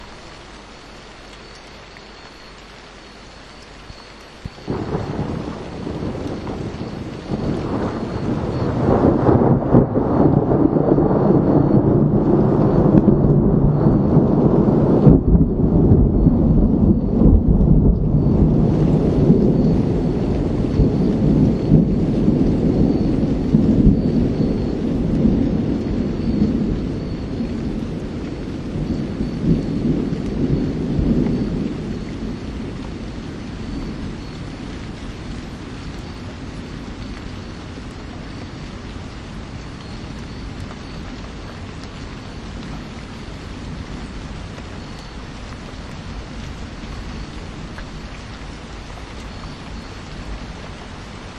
Another example from the shotgun mic, this time of the loudest thunder that I heard while out there. Listen carefully, because you’ll hear the rain vanish for a few moments:
Thunder through shotgun mic
But what I suspect now is the Olympus recorder has an auto-levels setting and it quashed the volume as it began to creep too high, resulting in the quieter rain dropping off.
ShotgunThunder2.mp3